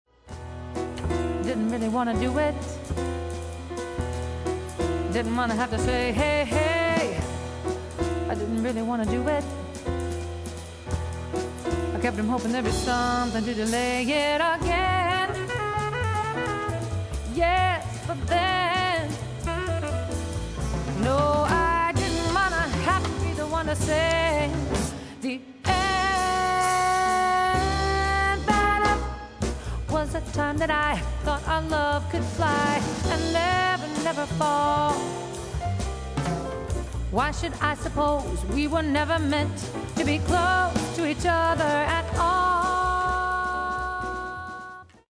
vocals
piano
saxes
bass
drums
guitar
and straight-ahead jazz still create musical magic.